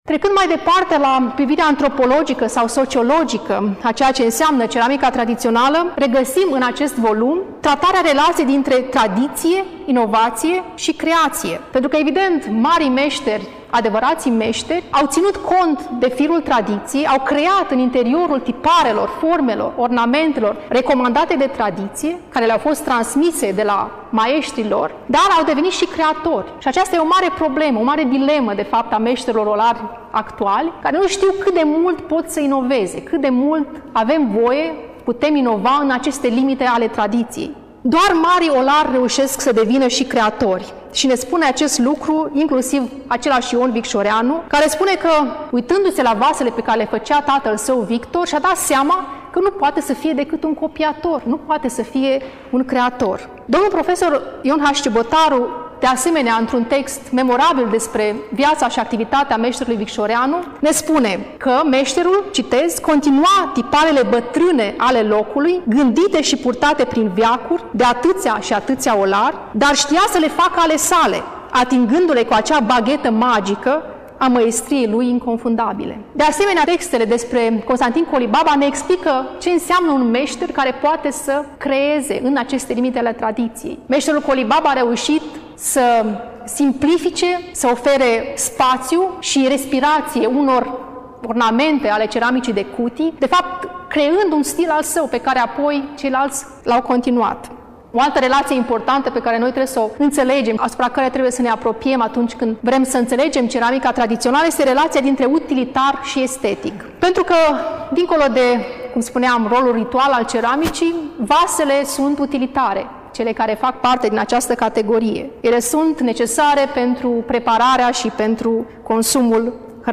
Până la final, difuzăm discursul